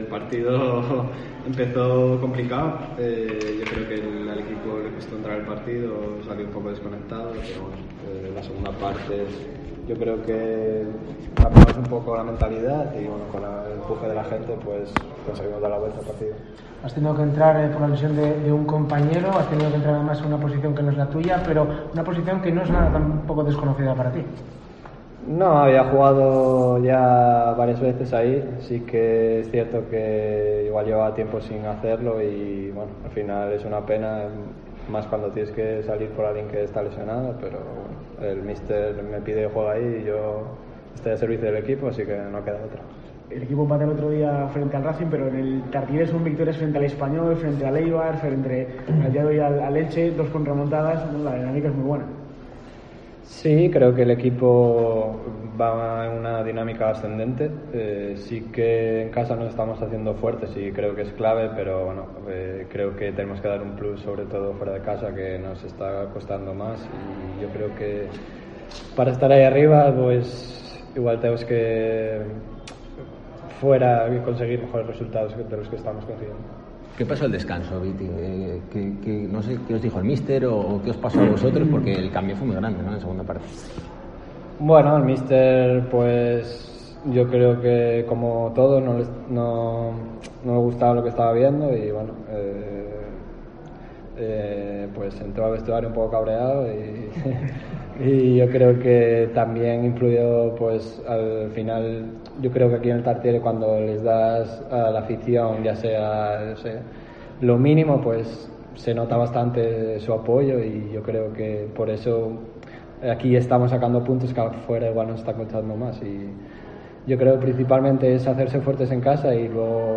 ZONA MIXTA